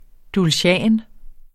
Udtale [ dulsjˈæˀn ]